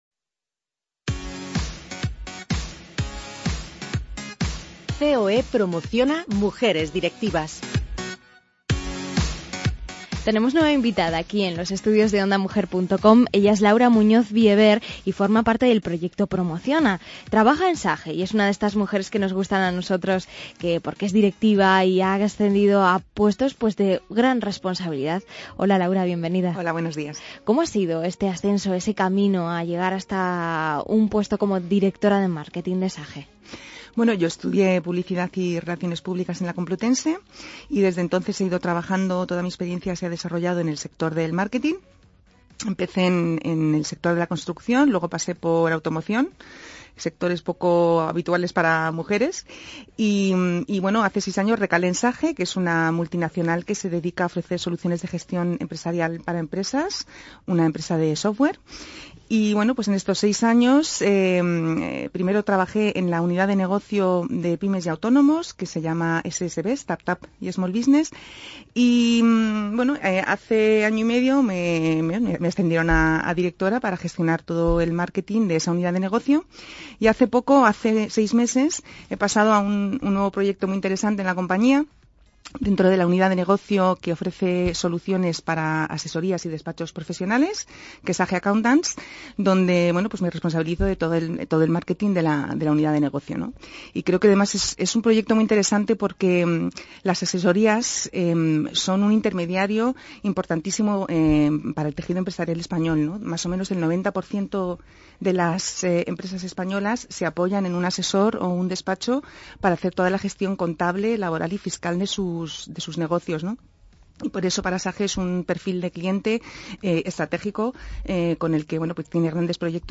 Como cada semana, y dentro de nuestro Espacio Proyecto Promociona, contamos en el estudio con una nueva directiva de renombre.